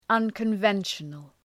Shkrimi fonetik{,ʌnkən’venʃənəl}